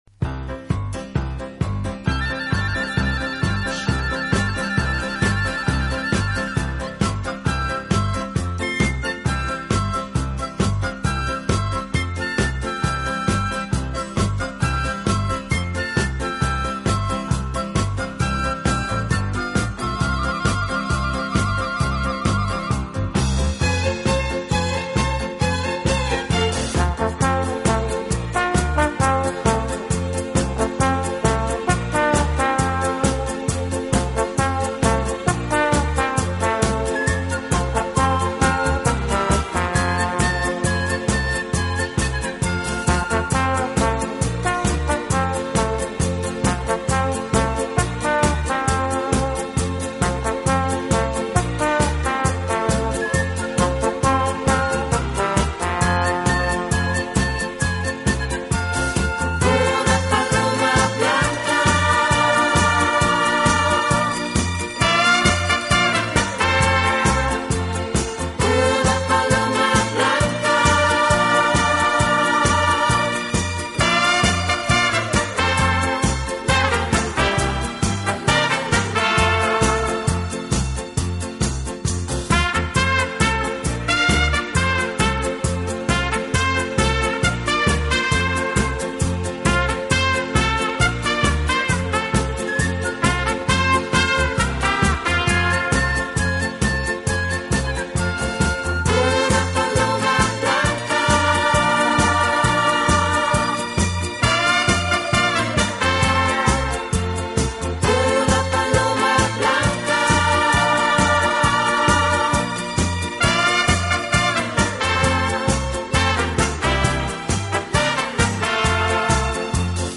乐器的演奏，具有拉美音乐独特的韵味。